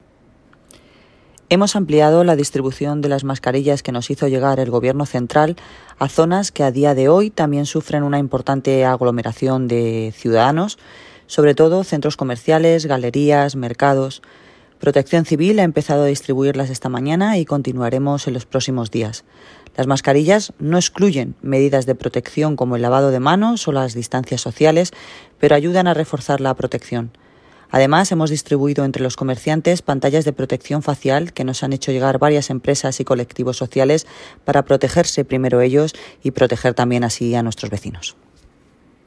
Noelia Posse - Declaraciones sobre reparto marcarillas